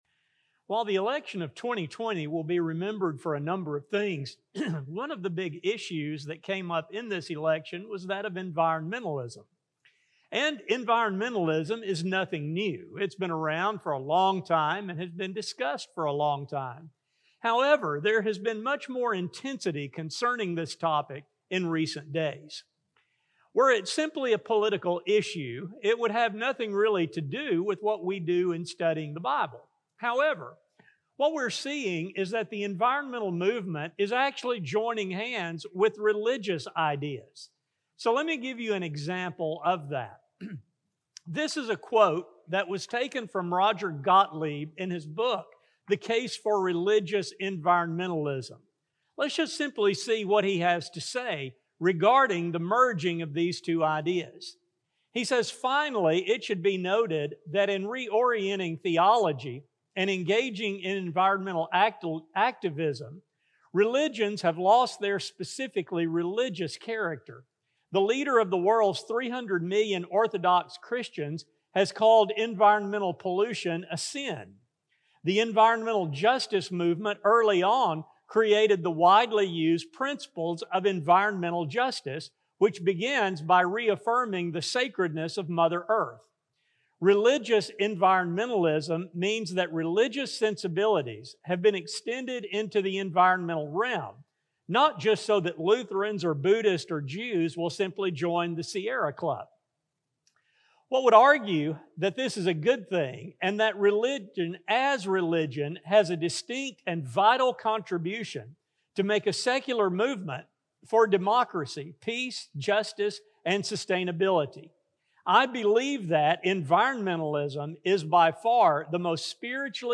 With this intense focus, it is important for the people of God to understand what God has spoken on this issue. A sermon recording